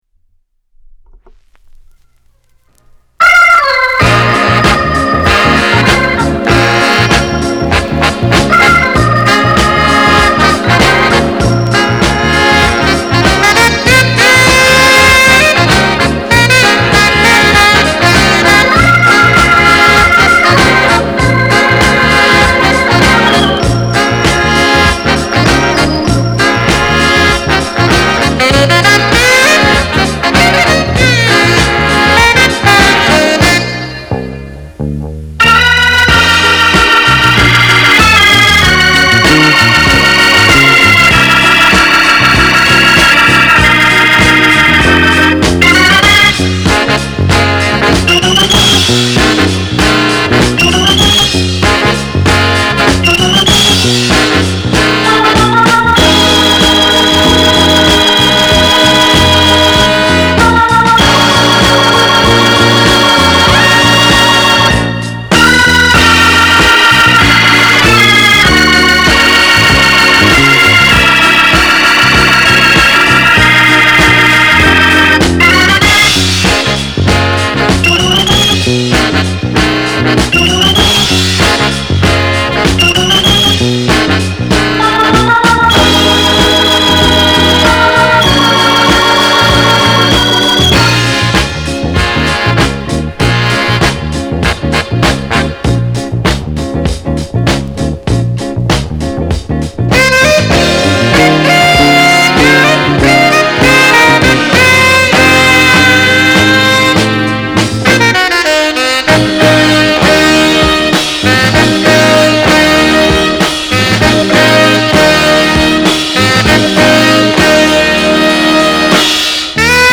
category R&B & Soul